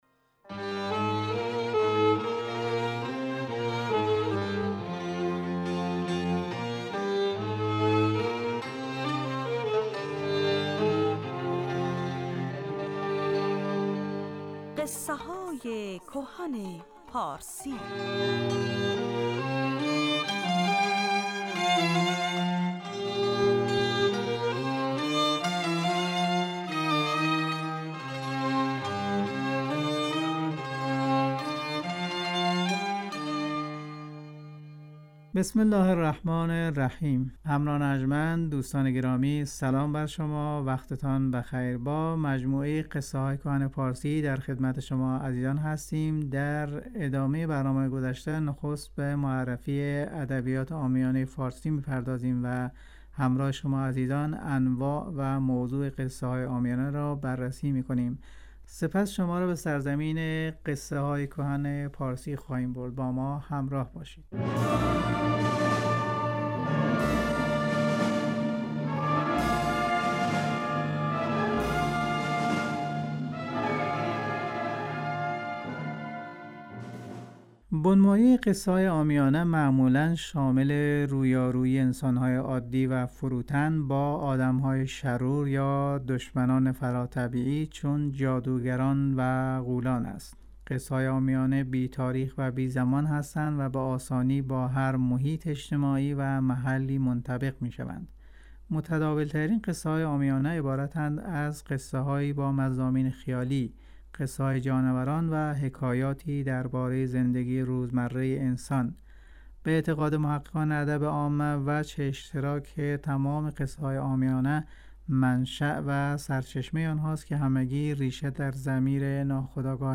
برنامه قصه های کهن پارسی چهارشنبه ها ساعت 2.15 دقیقه به وقت ایران پخش می شود. در بخش اول این برنامه به ادبیات پارسی پرداخته می شود و در بخش دوم یکی از داستان های هزار و یک شب روایت می شود.